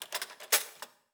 Babushka/SFX_Cutlery_06.wav at bug/scene_dependency - Babushka - Sneaky Elephant Games GiTea
SFX_Cutlery_06.wav